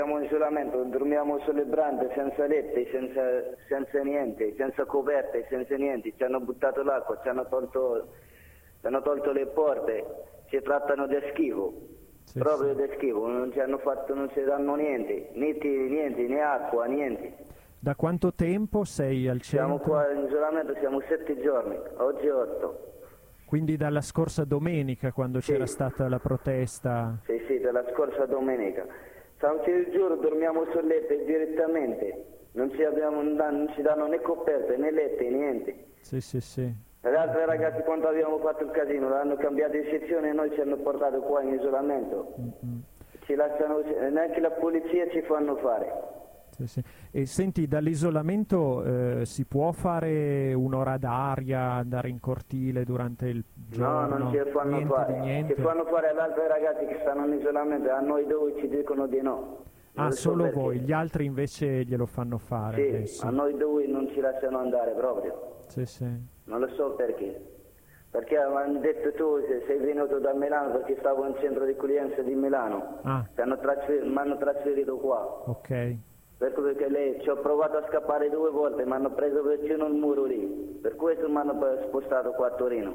Domenica 12 agosto in tarda mattinata un gruppo di antirazzisti ha portato l’impianto audio al mercato di Porta Palazzo (piazza della Repubblica), frequentato da moltissimi cittadini africani, e fatto ascoltare Radio Blackout mentre venivano fatti dei collegamenti telefonici con i prigionieri nel CIE di corso Brunelleschi.
Ascolta alcune voci dei prigionieri: